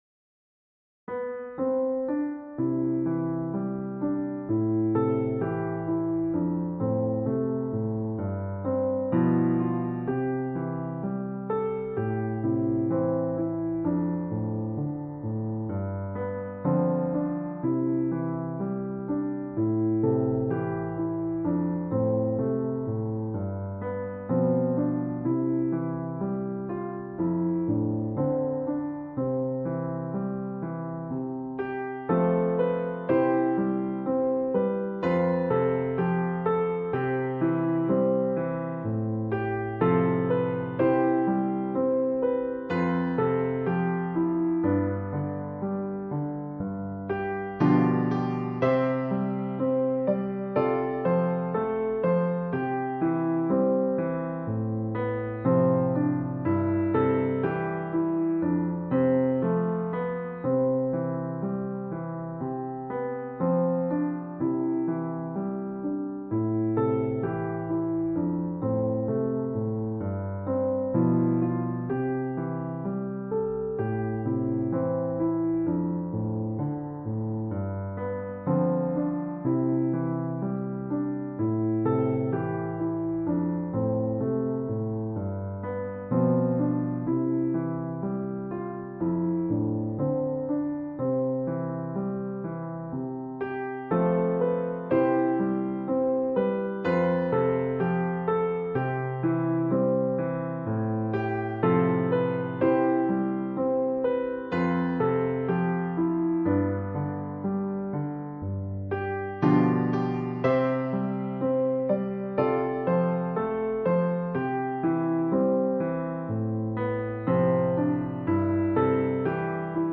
Irish folk song
solo piano